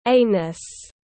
Hậu môn tiếng anh gọi là anus, phiên âm tiếng anh đọc là /ˈeɪ.nəs/.
Anus /ˈeɪ.nəs/